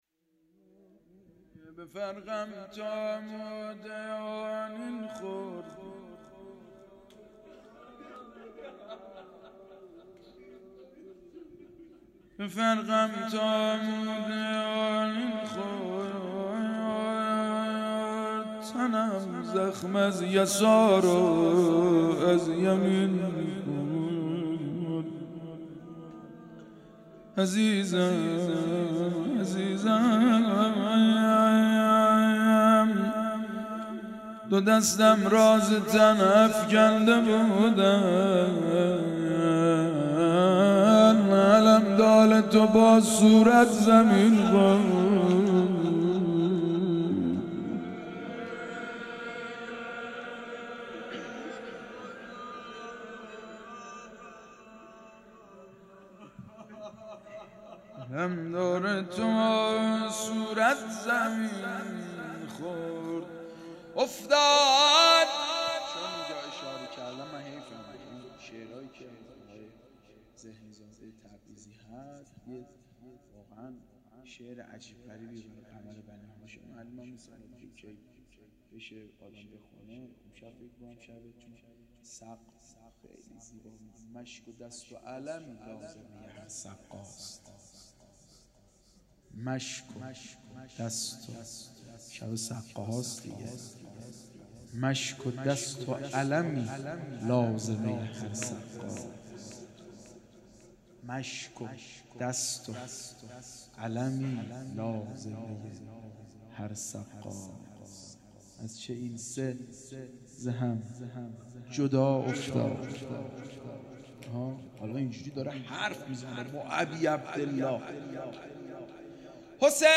مراسم شب هفدهم ماه رمضان با مداحی
درمسجد کربلا برگزار گردید.